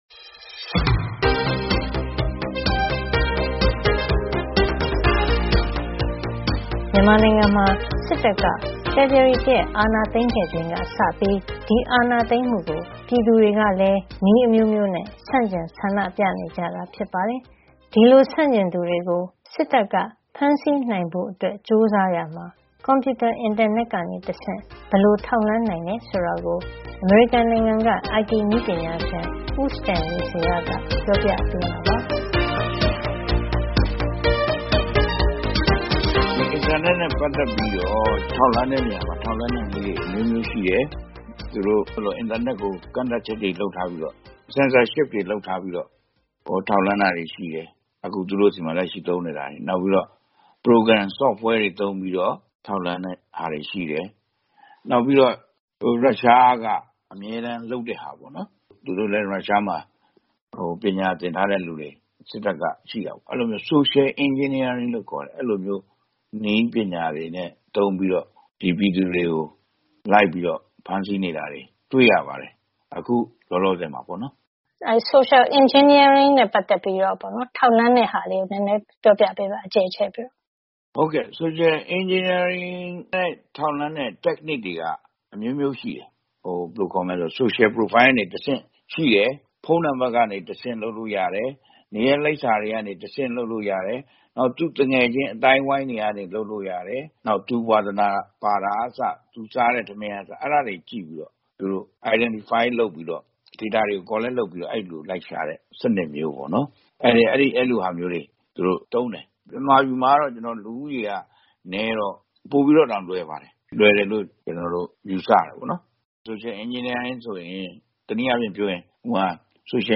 by ဗွီအိုအေသတင်းဌာန